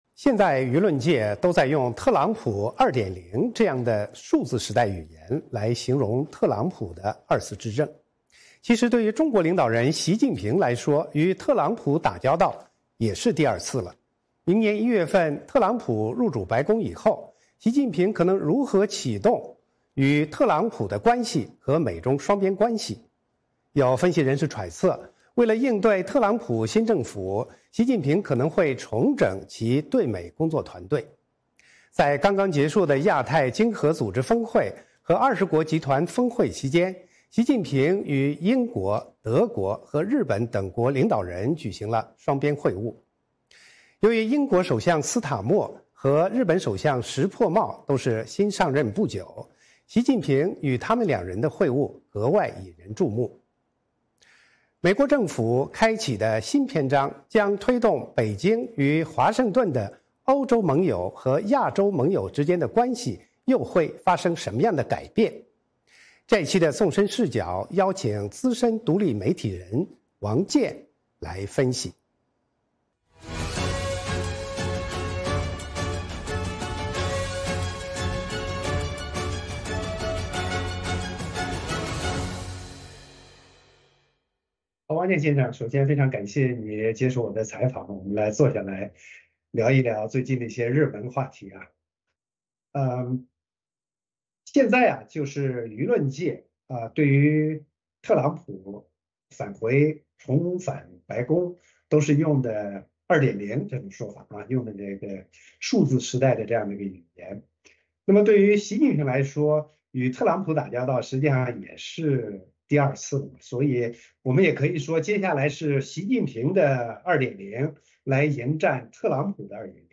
《纵深视角》节目进行一系列人物专访，受访者所发表的评论不代表美国之音的立场。